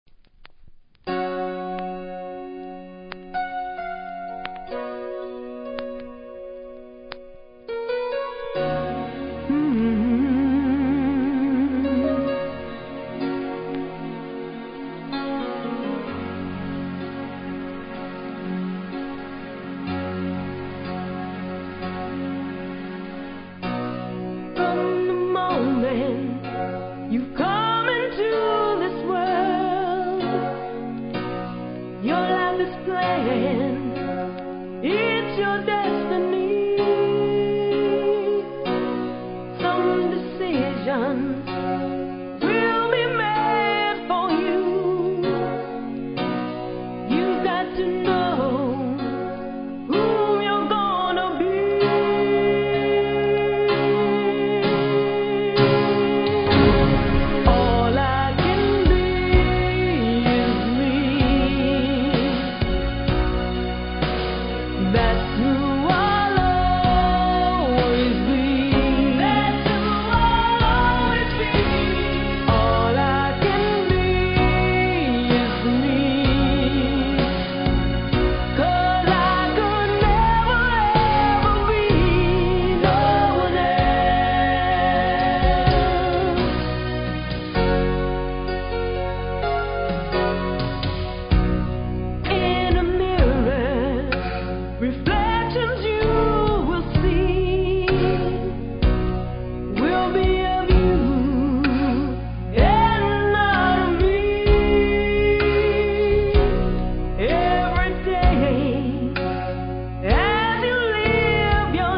JAMAICAN SOUL ��199?
FEMALE